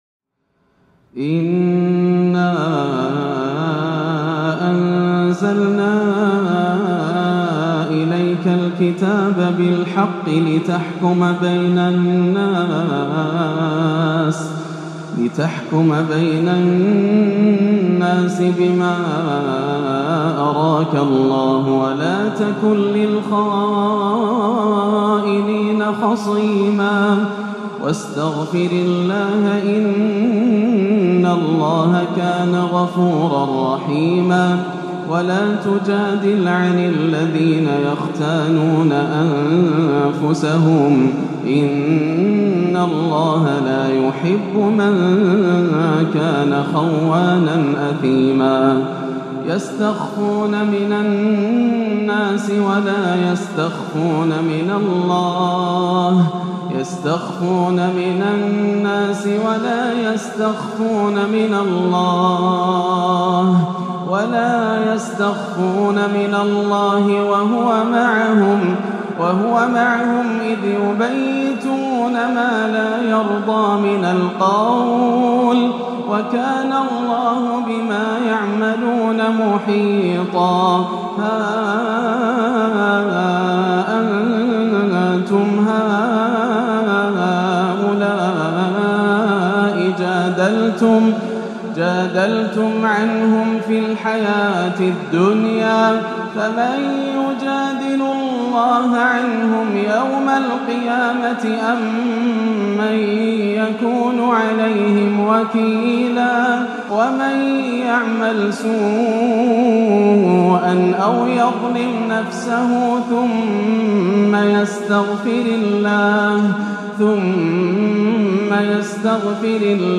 آيات التحذير من الشيطان وأساليبه - تألق في تصويرها شيخنا من سورة النساء - السبت 24-11 > عام 1437 > الفروض - تلاوات ياسر الدوسري